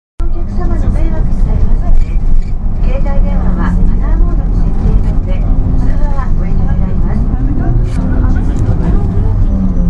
音声合成装置  クラリオン(ディスプレイ１)